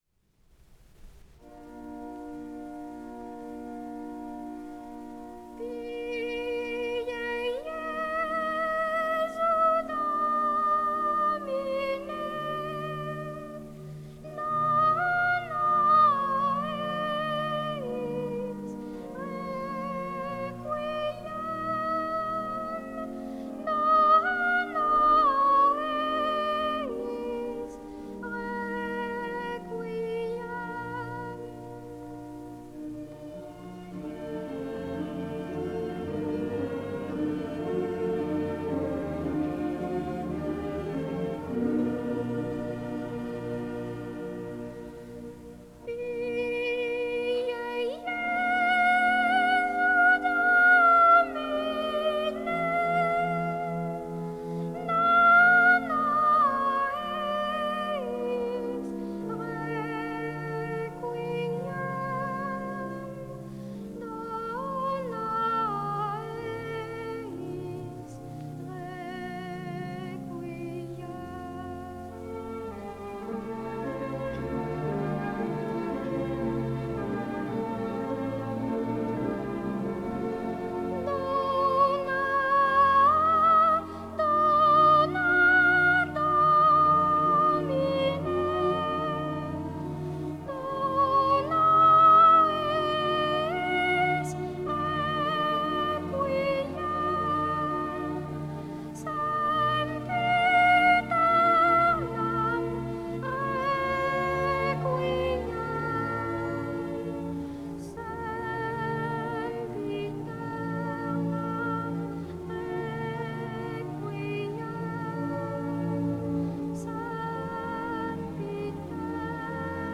소프라노(또는 소년 소프라노) 독창으로, 영원한 안식을 비는 내용이다.
첫 부분은 변라장조의 6마디 선율이며, 두 번째 부분은 비슷하지만 더 높은 음역에 이른다.
오케스트라는 독창의 여운처럼 감싸 안는다.